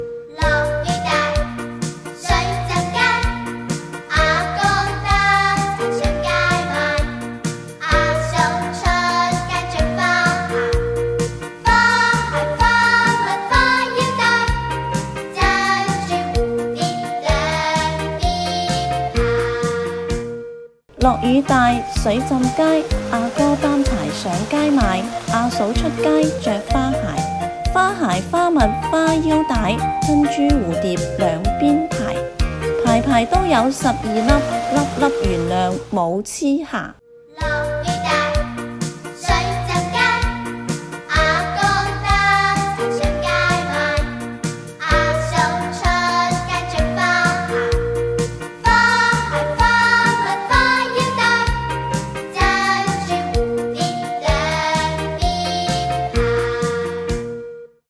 【广府童谣】